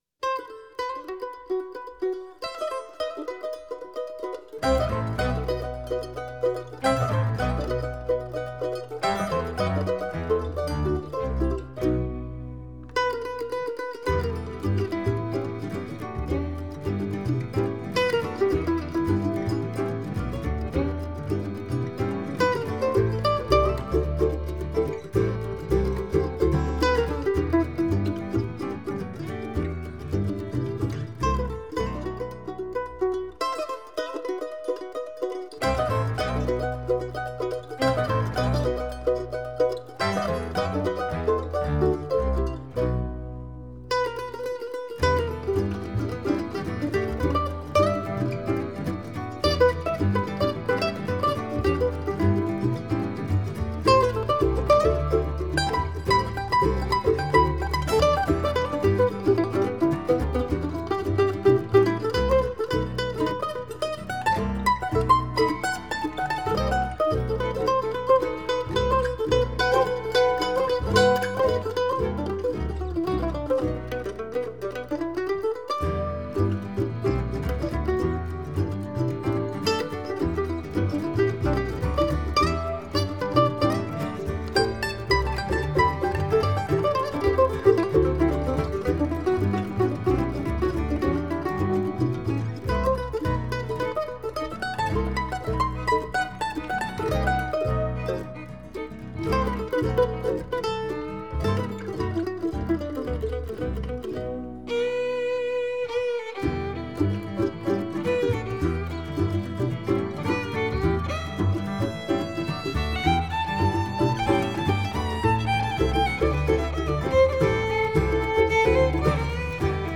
with its inspired bluegrass fusion
violin
bass
second mandolin